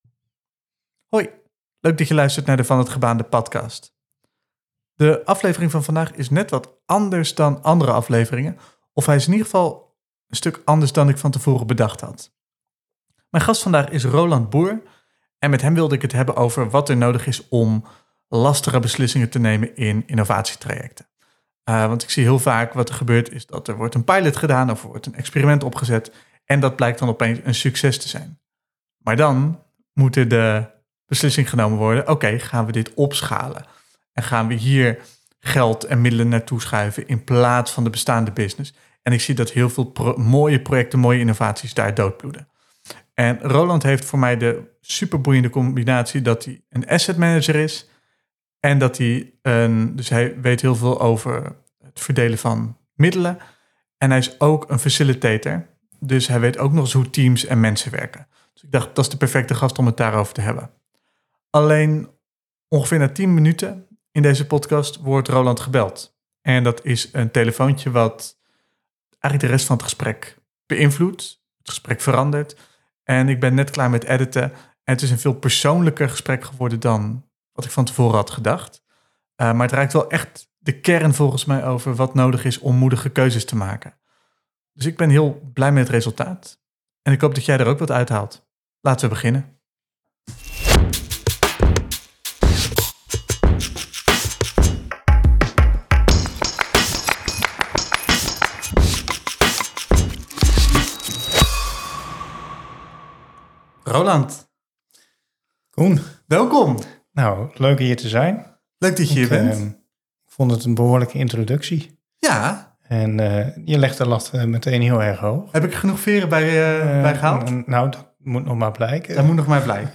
in gesprek met een inspirerende gast.